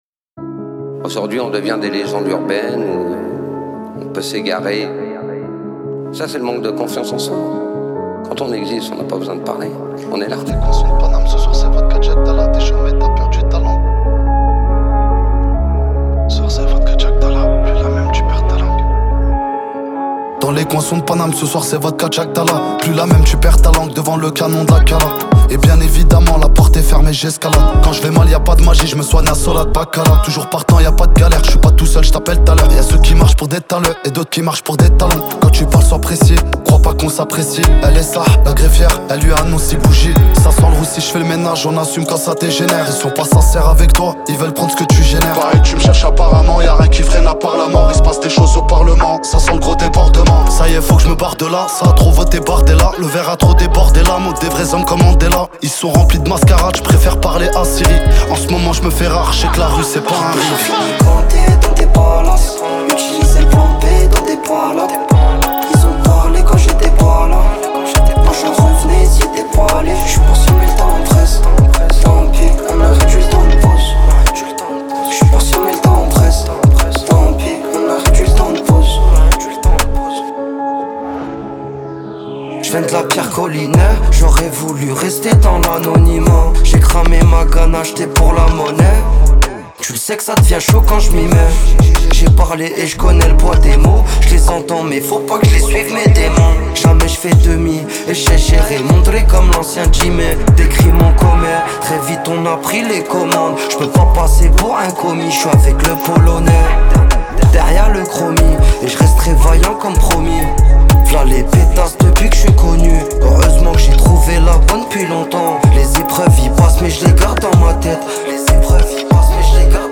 italian trap Télécharger